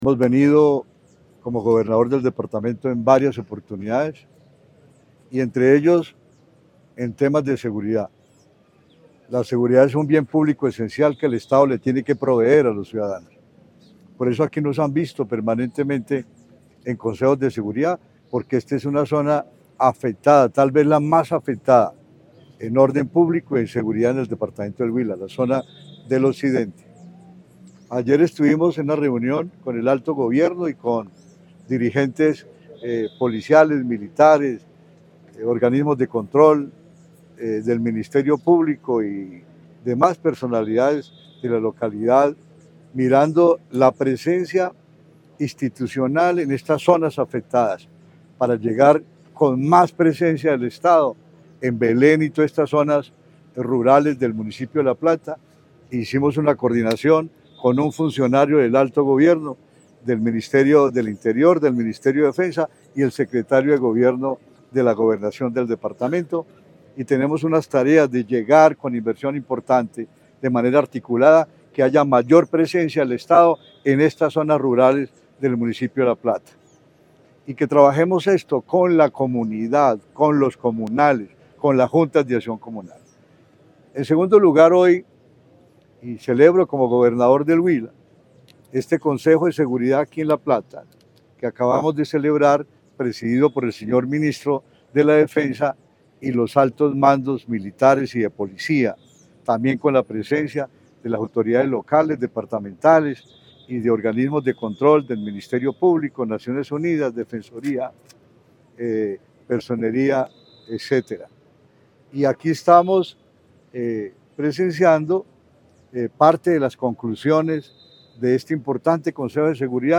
1.-Rodrigo-Villalba-M-Gobernador-del-Huila.mp3